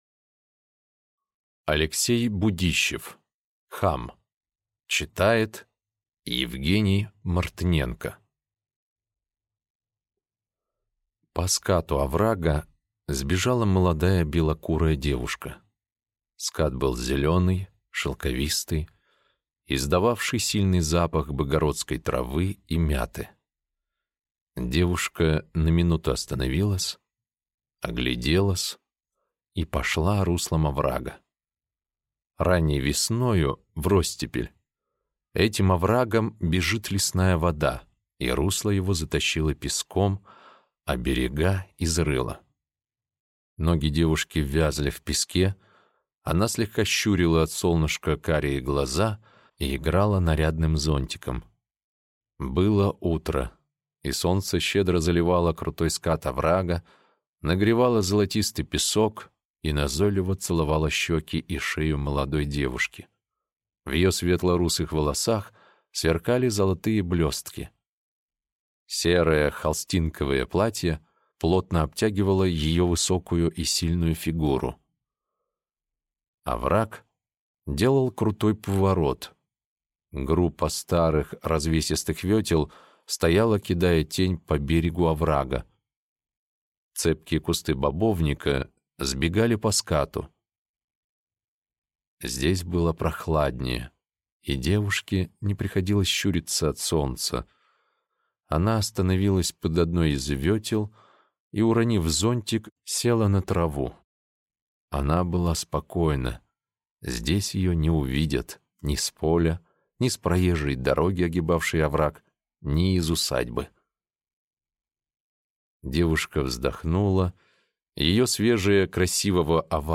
Аудиокнига Хам | Библиотека аудиокниг